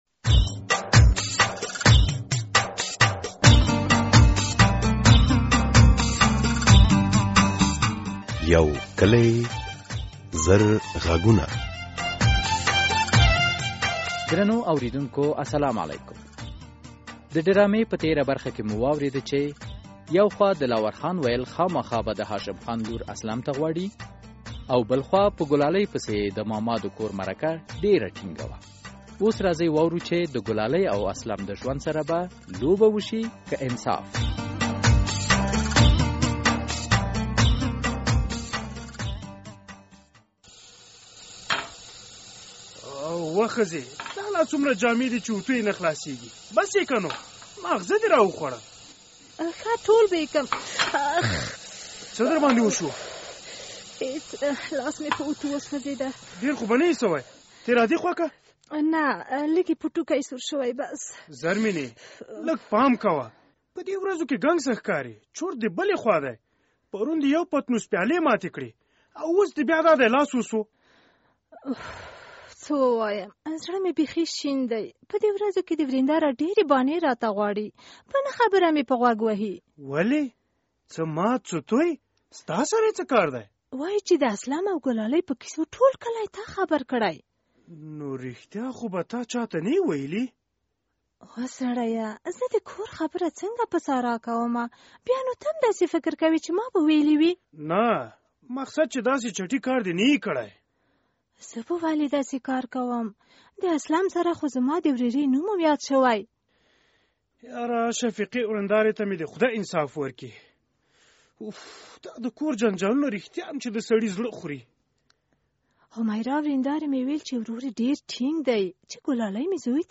یو کلي او زرغږونه ډرامه هره اوونۍ د دوشنبې په ورځ څلور نیمې بجې له ازادي راډیو خپریږي.